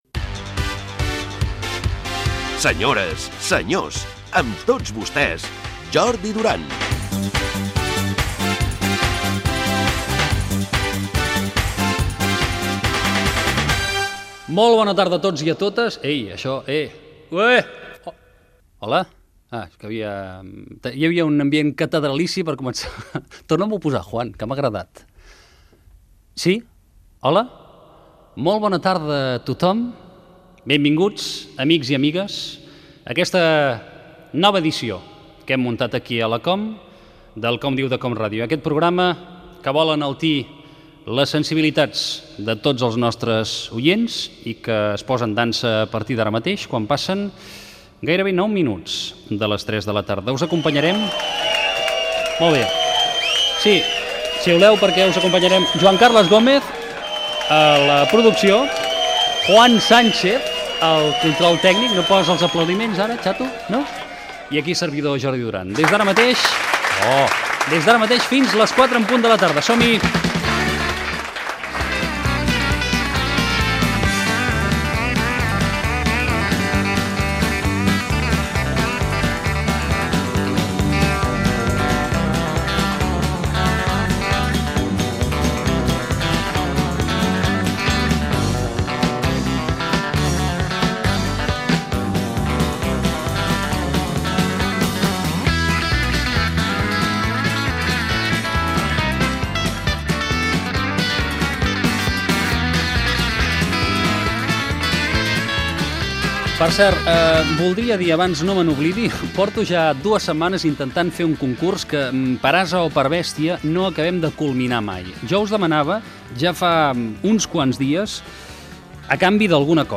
Careta del programa, presentació inicial i fragment d'una conversa amb el nedador David Meca
Entreteniment